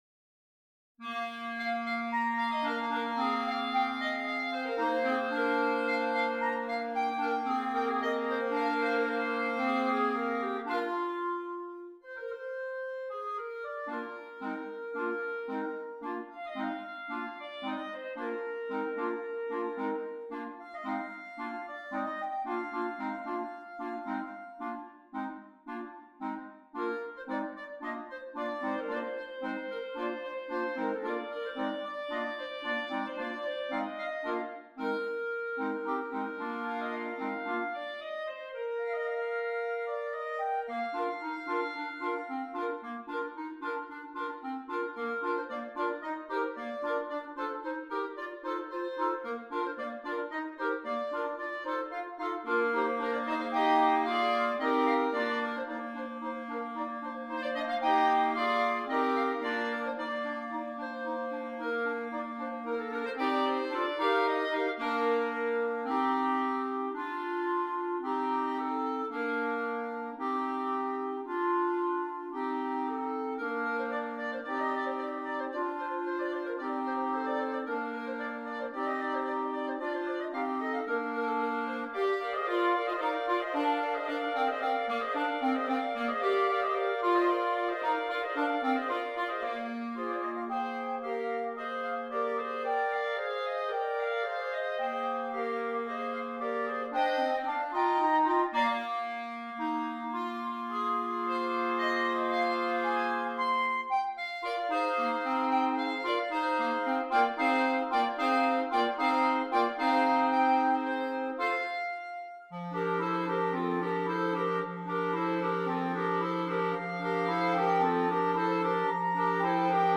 5 Clarinets